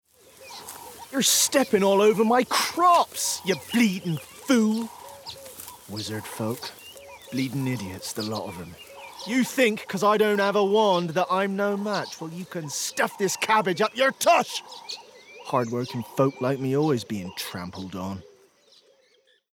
20/30's London,
Contemporary/Friendly/Warm
Gaming Showreel Elf King
Rural Folk Stoner